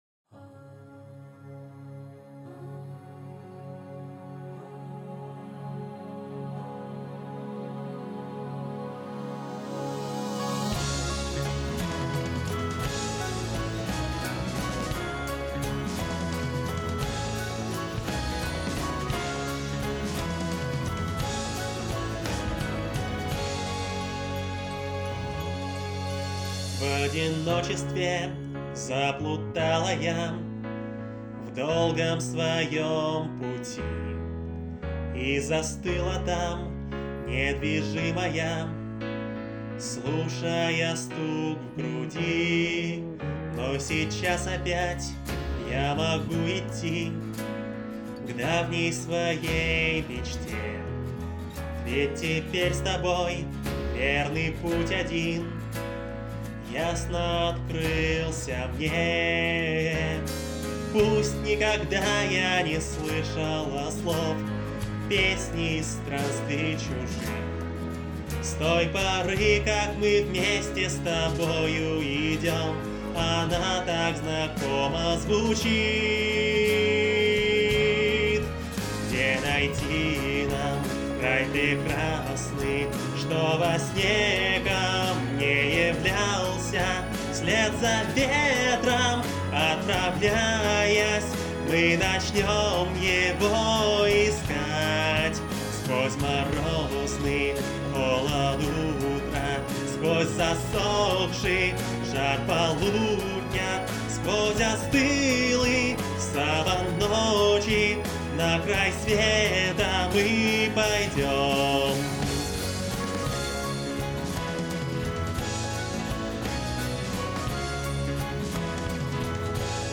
Впервые пробую записывать голос непосредственно под минус.
Я сегодня не в голосе, но свёл достойно.
О, ты все-таки спел под минус.
Норм звучит, крутяк.